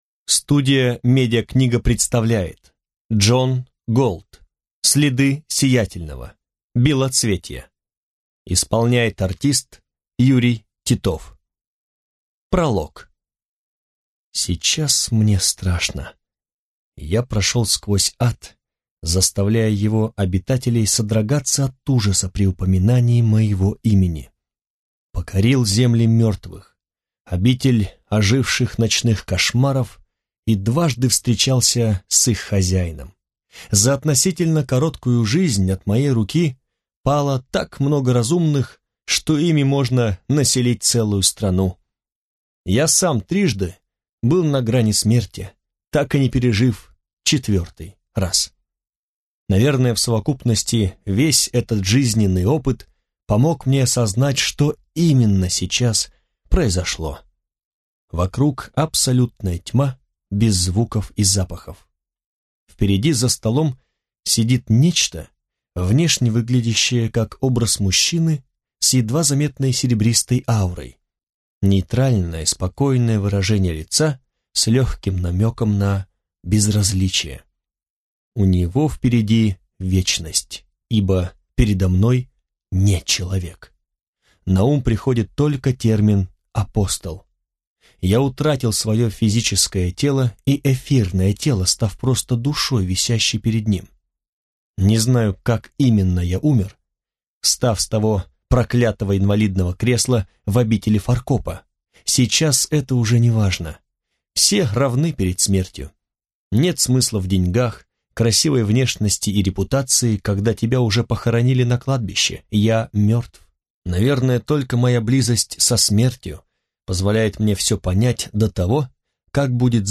Аудиокнига Следы Сиятельного. Белоцветье | Библиотека аудиокниг